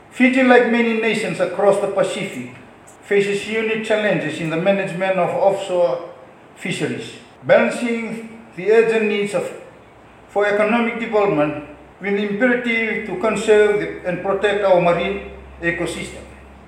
While opening the ‘Improving Fisheries Transparency in Fiji’ workshop in Lami this morning, Kalaveti Ravu says the country’s Exclusive Economic Zone spans over 1.29 million square kilometers, comprising various marine resources and ecosystems that must be protected.